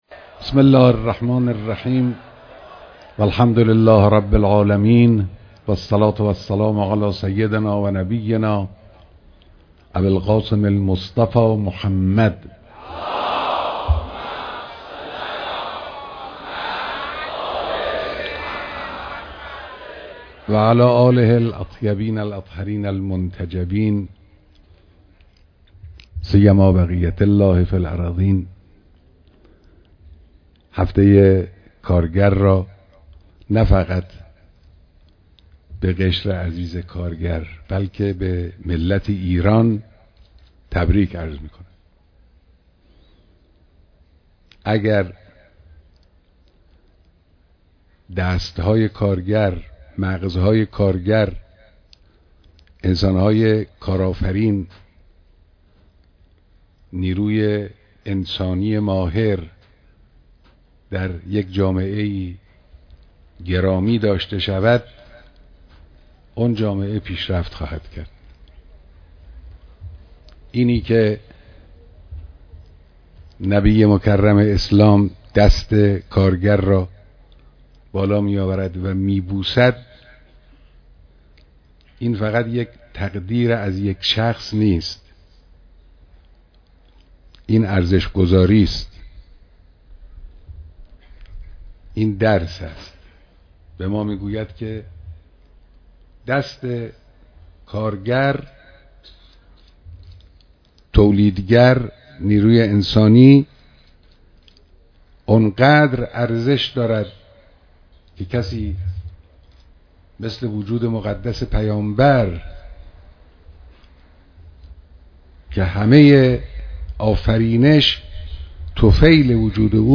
بيانات در اجتماع كارگران در كارخانجات توليدى داروپخش‌